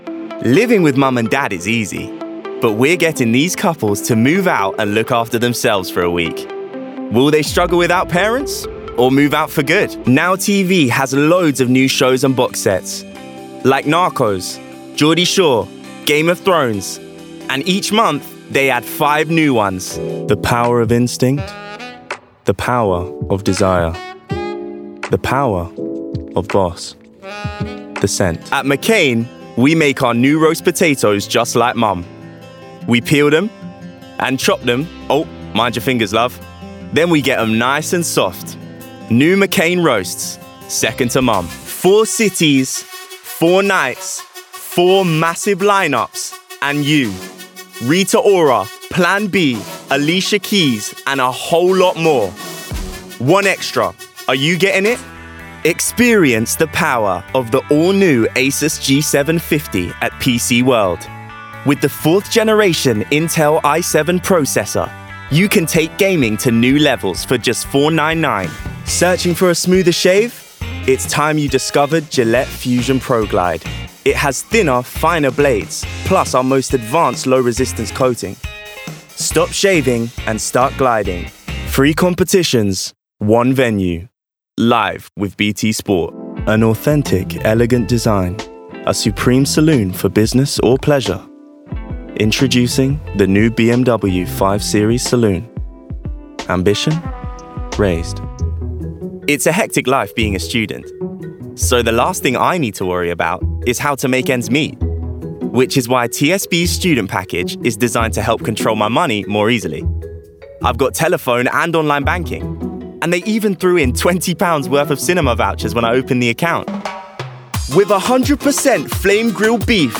Commercial Reel
London, RP ('Received Pronunciation')